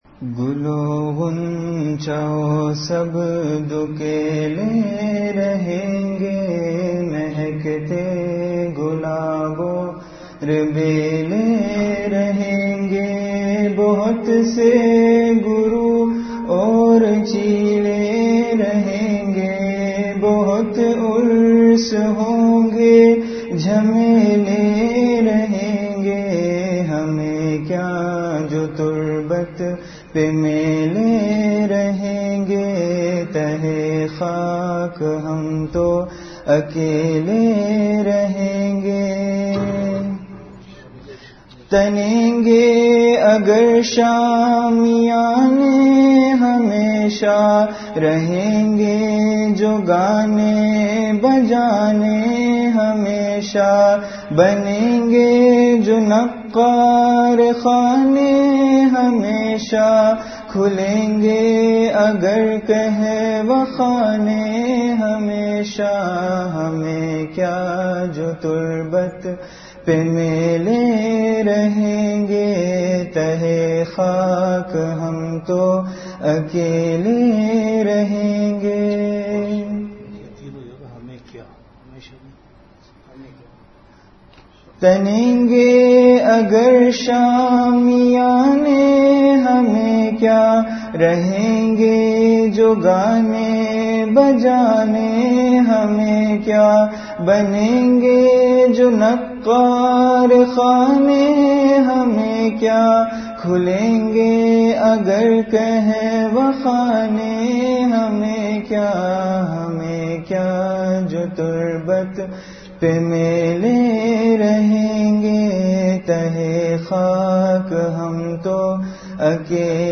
Delivered at Home.
Majlis-e-Zikr
After Magrib Prayer